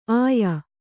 ♪ āya